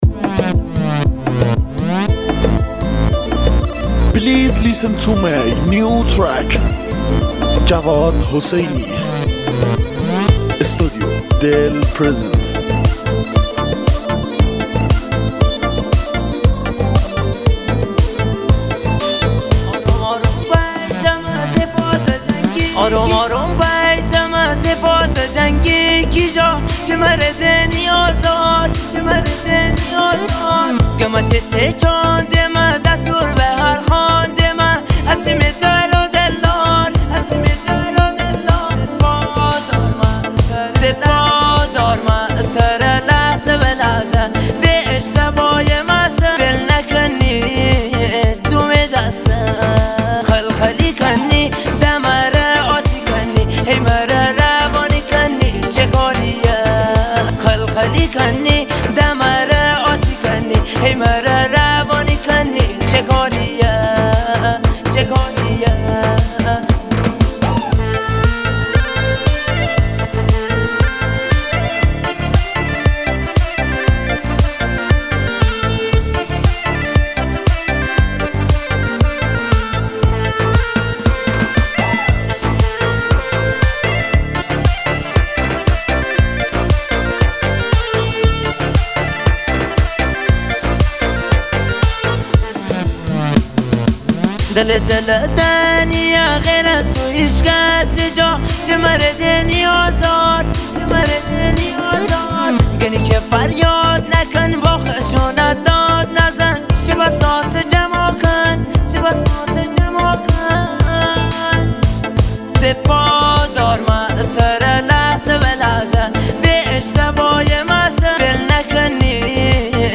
دانلود آهنگ مازندرانی